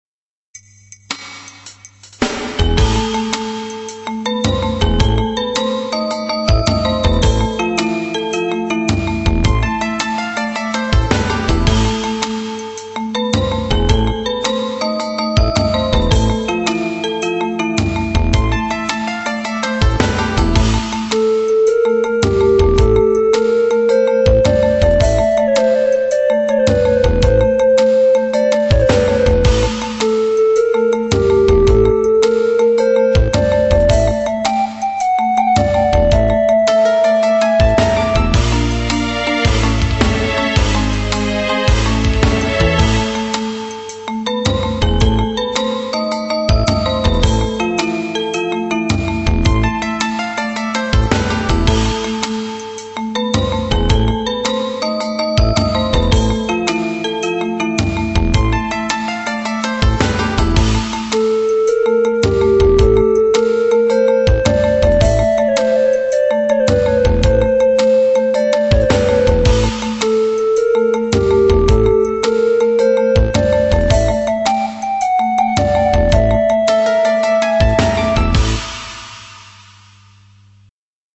音質下げてます、ご了承下さい。